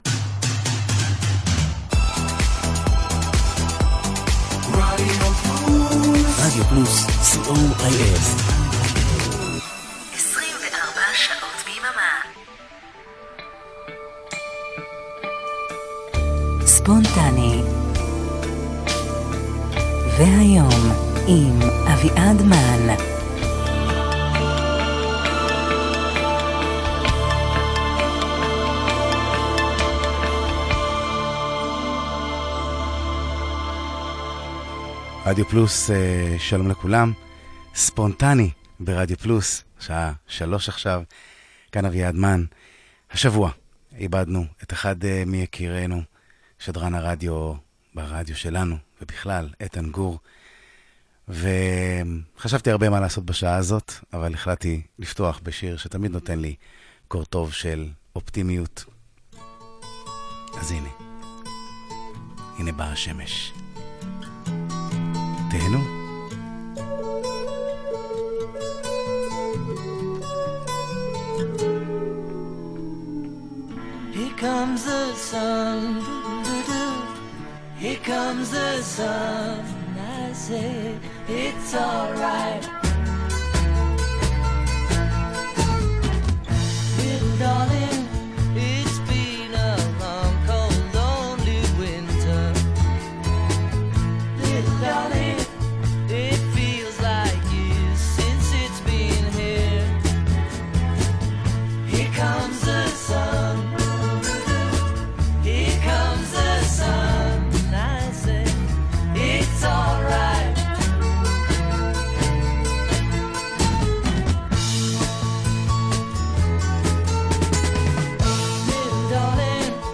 🙂 כמה שירים רגועים (יחסית) לסיום שבוע לא פשוט ובתקווה לשבת טובה ושקטה שבת שלום!